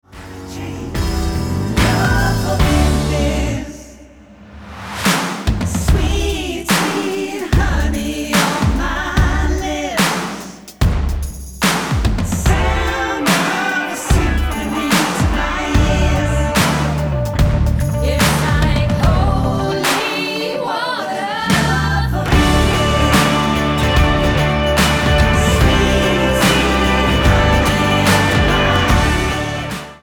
Tonart:D mit Chor
Die besten Playbacks Instrumentals und Karaoke Versionen .